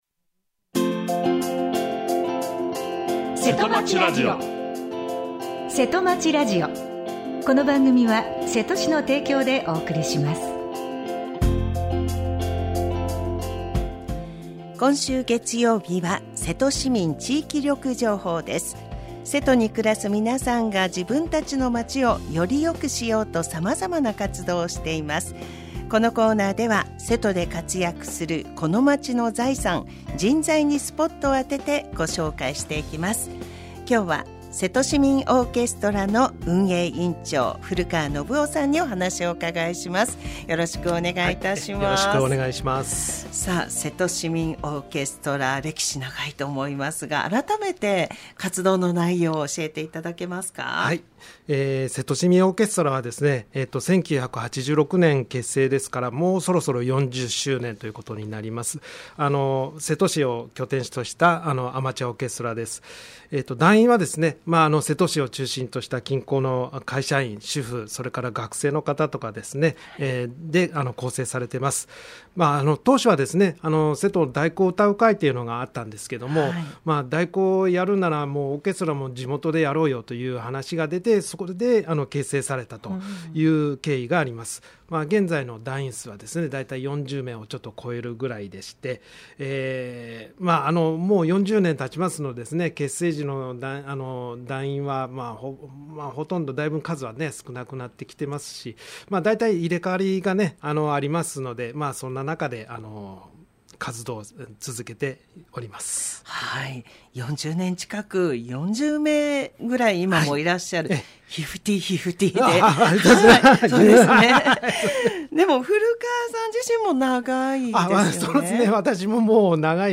, 生放送 | radiosanq-hp | 2024年12月9日 9:30 AM | 2024年12月9日（月） は コメントを受け付けていません